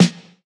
Tuned drums (F key) Free sound effects and audio clips
• Steel Snare Drum Sample F Key 389.wav
Royality free snare sound tuned to the F note. Loudest frequency: 1015Hz
steel-snare-drum-sample-f-key-389-Gsc.wav